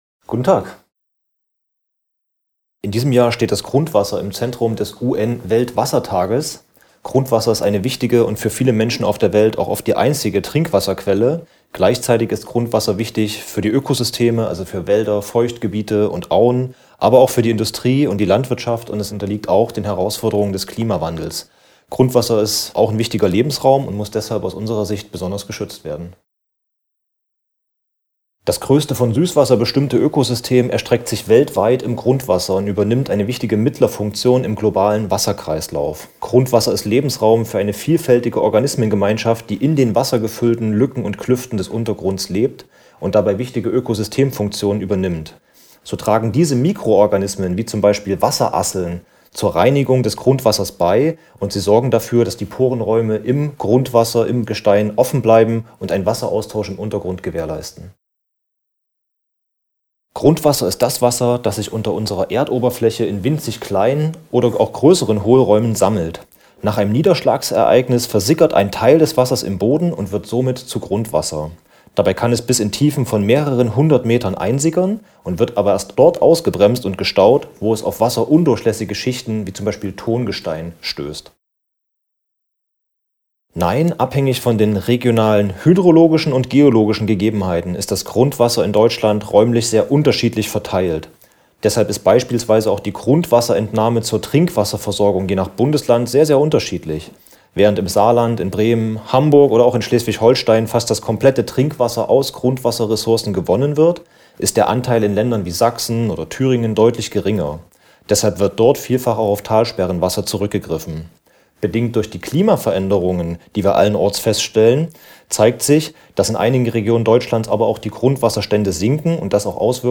Interview: 3:25 Minuten